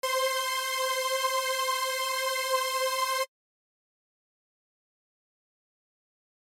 The result is an even thicker bigger sound.
If you listen very carefully you will hear just a tiny phasing effect in addition to the widened stereo field.